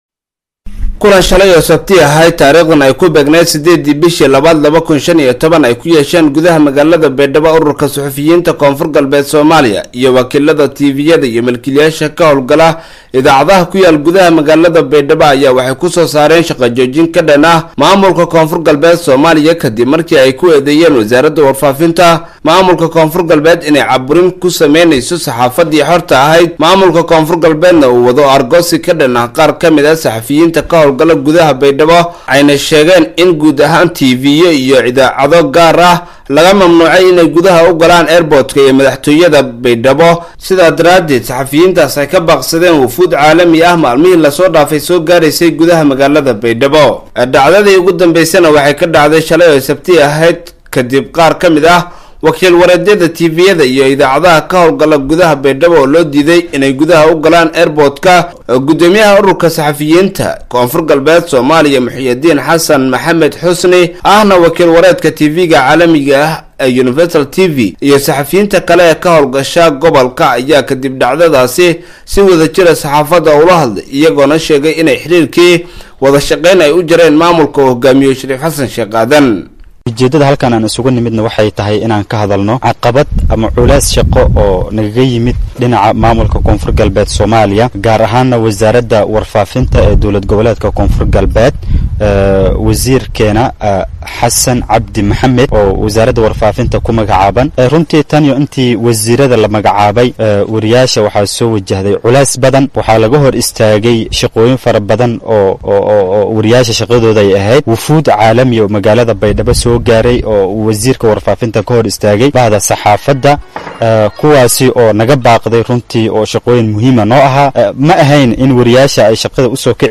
old-Kulanka-Saxaafada.m4a